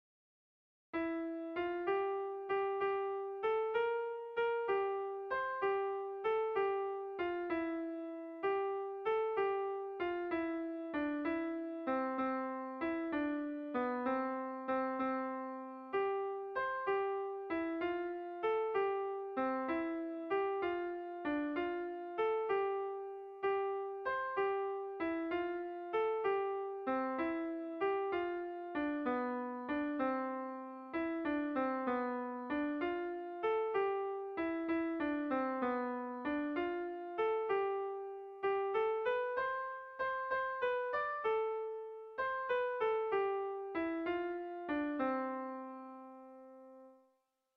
Dantzakoa
Eugi < Esteribar < Ibarrak < Iruñerria < Nafarroa < Euskal Herria
Zortziko ertaina (hg) / Lau puntuko ertaina (ip)
ABD1D2